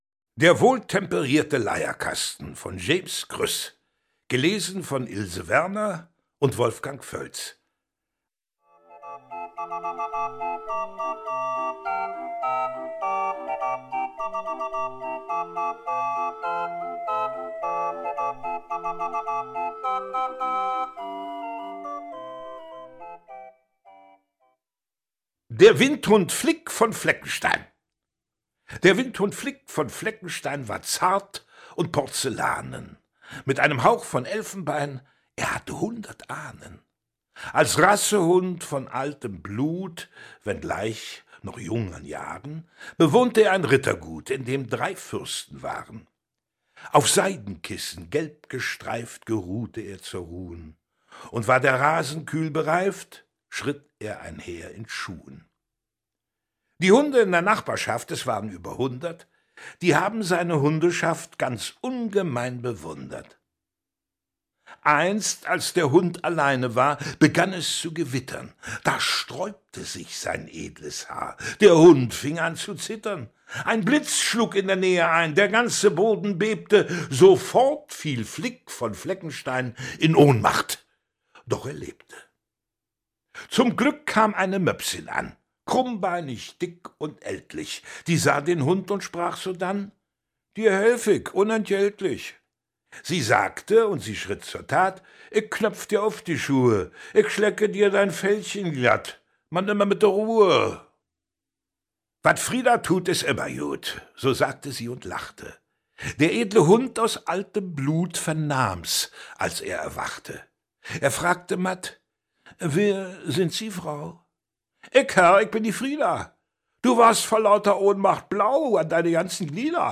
Gedichte für Kinder, Erwachsene und andere Leute, Sprecher: Ilse Werner, Wolfgang Völz, 1 CD 62 Min.
Wolfgang Völz, Ilse Werner (Sprecher)
Neben der Schauspielerei ist Völz vor allem wegen seiner markanten Stimme als Synchronsprecher gefragt.
Erich Kästner, 1961 "Krüss' feiner Humor, seine pointierte Beobachtungsgabe, seine Freude an Sprachspielereien und seine unbändige Fantasie zeichnen seine Gedichte aus - das alles wird kongenial von den Schauspielern Wolfgang Völz und Ilse Werner gesprochen, untermalt von Leierkastenmusik."